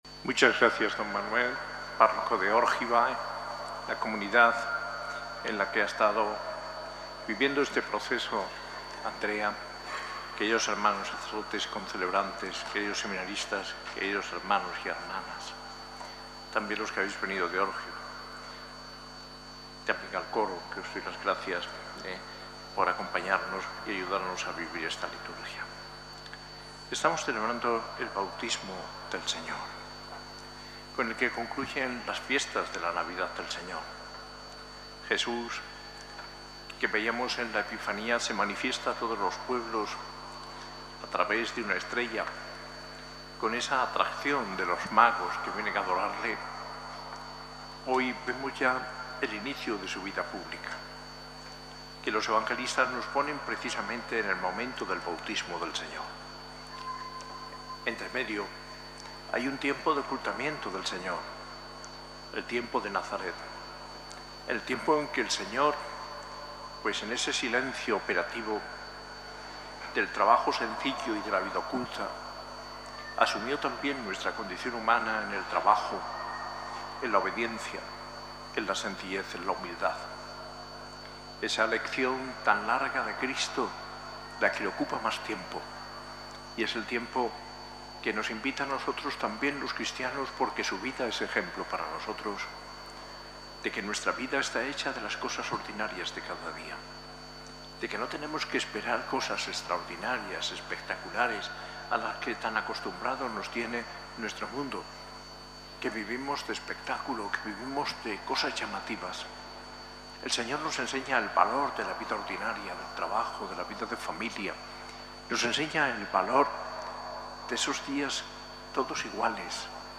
Homilía del arzobispo Mons. José María Gil Tamayo en la Eucaristía del día del Bautismo del Señor, el 12 de enero de 2025, celebrada en la S.A.I Catedral.